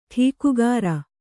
♪ ṭhīkugāra